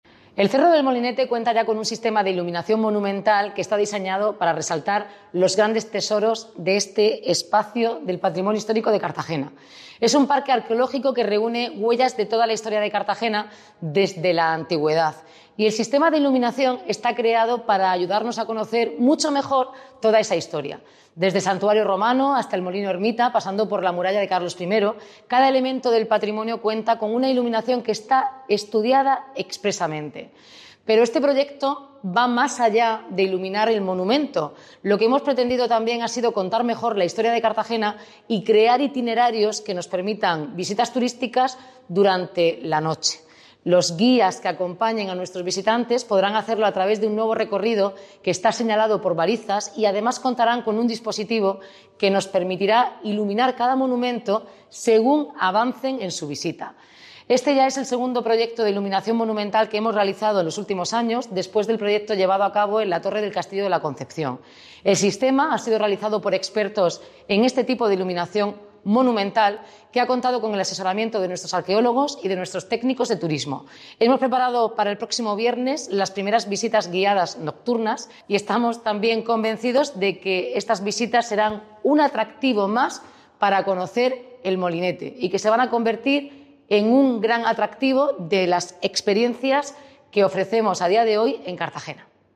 Enlace a Declaraciones de la alcaldesa, Noelia Arroyo, sobre la nueva iluminación del Molinete